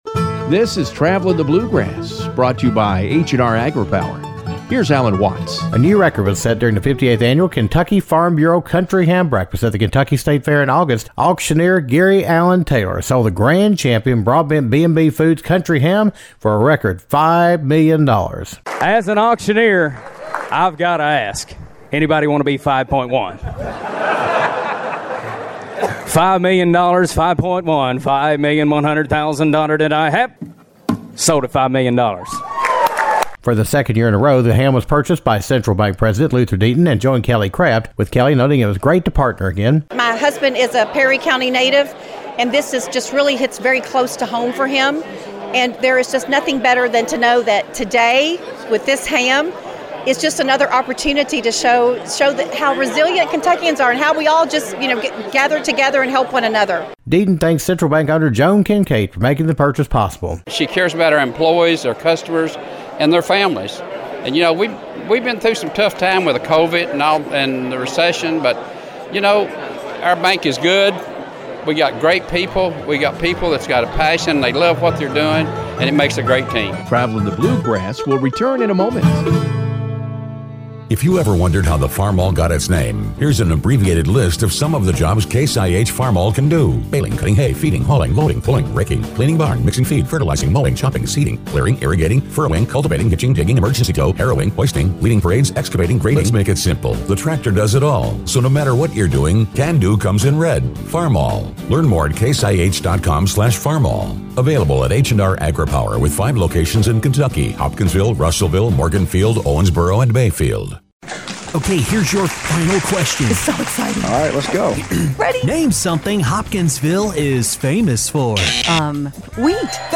The Grand Champion Kentucky Country Ham sold for a record $5 million during the 58th Annual Kentucky Farm Bureau Country Ham Breakfast at the Kentucky State Fair.